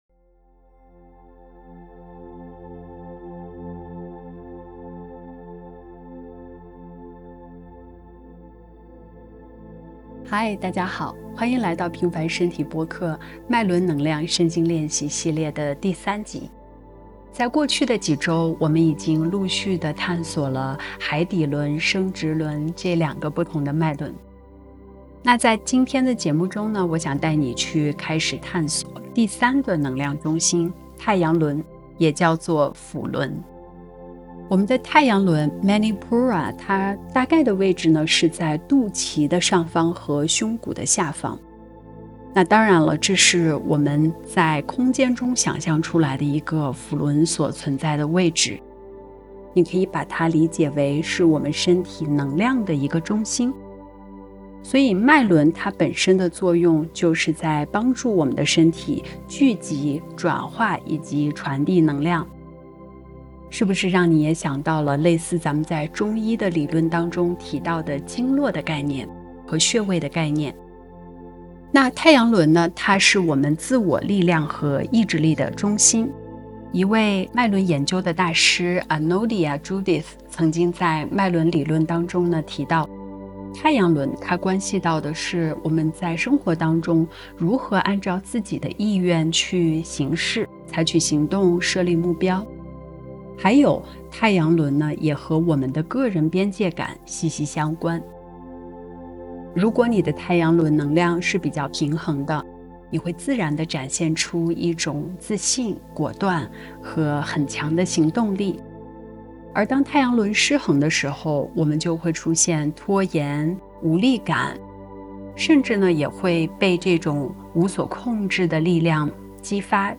脉轮能量身心冥想｜太阳轮：行动力与自我意志中心